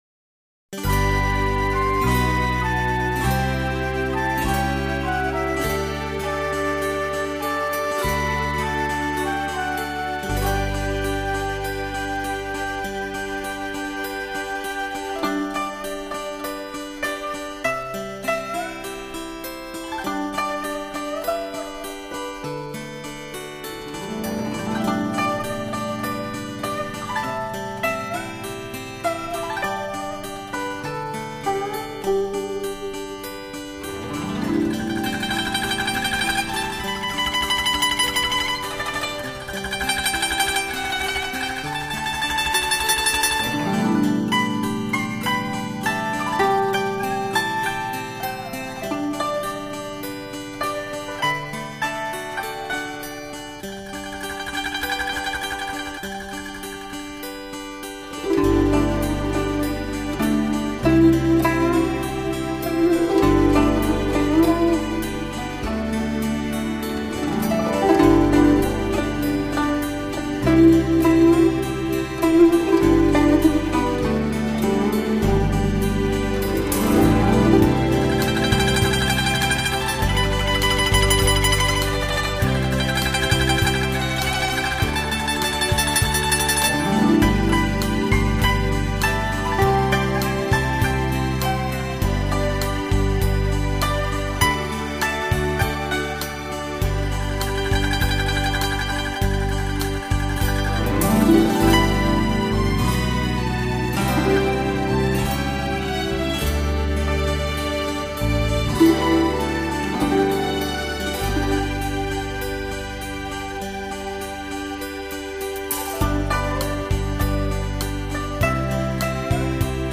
15/1/2011]纯音乐-
耳熟能 详的曲目用古筝、二胡、笛子等演绎。